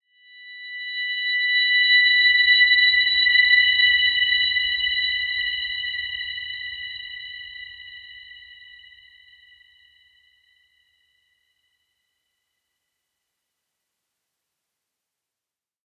Dreamy-Fifths-B6-f.wav